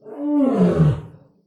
sounds_lion_bellow.ogg